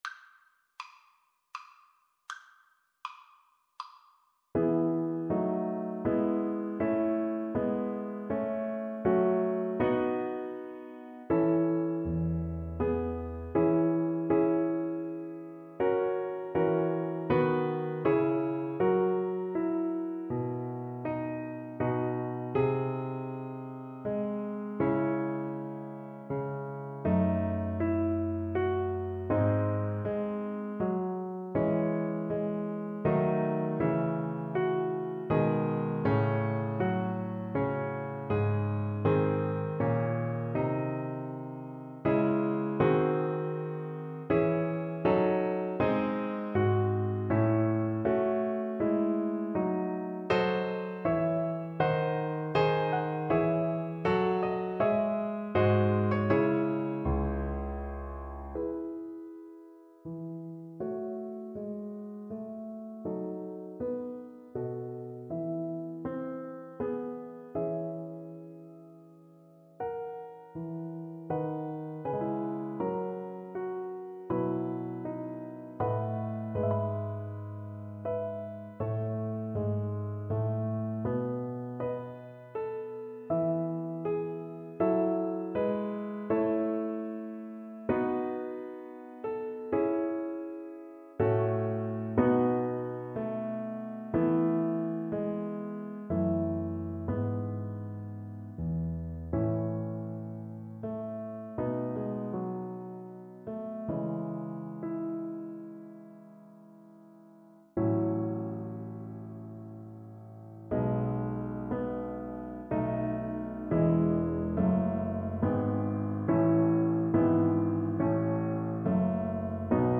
Play (or use space bar on your keyboard) Pause Music Playalong - Piano Accompaniment Playalong Band Accompaniment not yet available transpose reset tempo print settings full screen
Violin
3/4 (View more 3/4 Music)
E major (Sounding Pitch) (View more E major Music for Violin )
Andantino =80 (View more music marked Andantino)
Classical (View more Classical Violin Music)